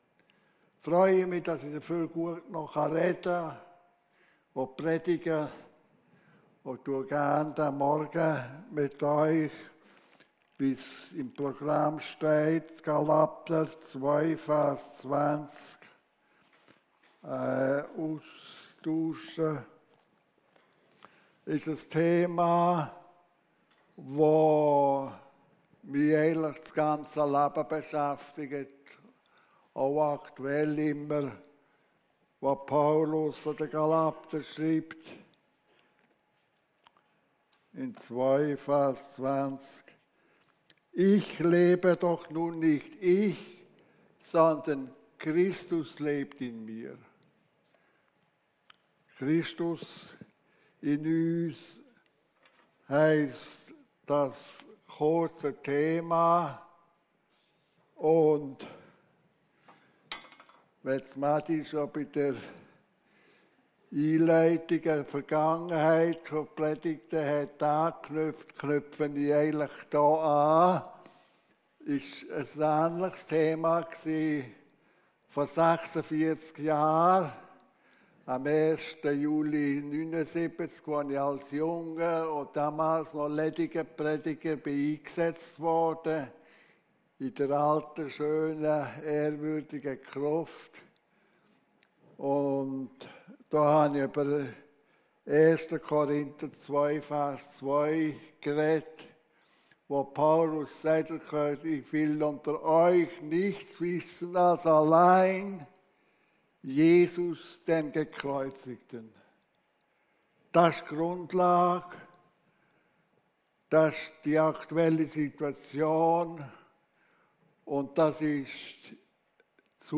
Hier hörst du die Predigten aus unserer Gemeinde.